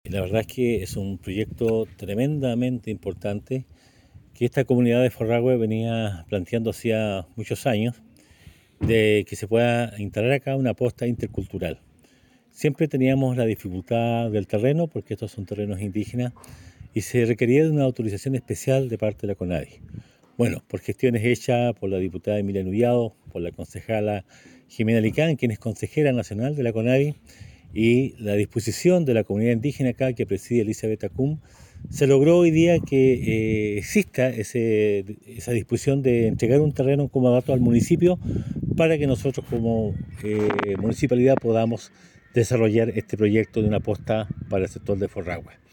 El compromiso consiste en que la comunidad cederá parte de su terreno al Municipio, para que éste gestioné el proyecto para instalar una Posta Intercultural en su sector, lo que vendría a suplir una necesidad importante para vecinos y vecinas, como lo explicó el Alcalde Carrillo.
19-agosto-23-emeterio-carrillo-forrahue.mp3